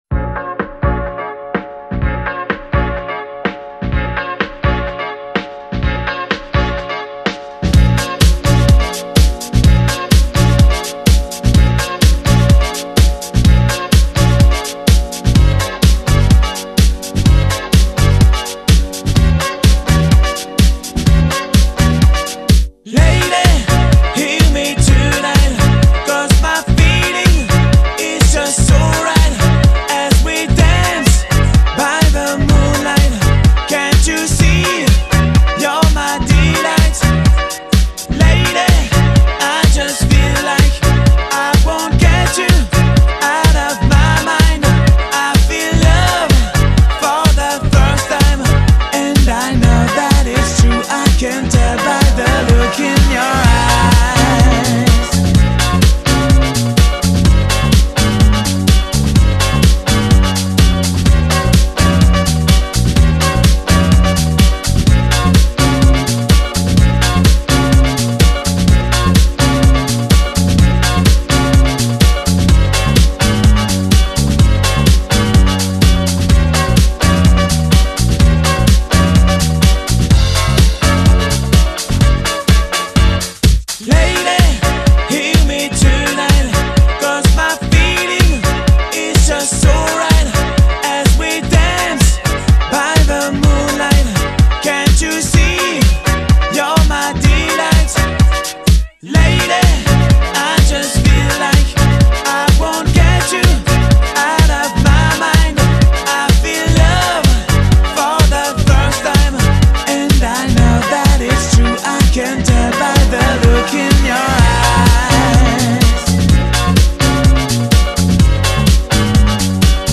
French House / Disco
ملودی گرم و رقص‌پذیرش
شاد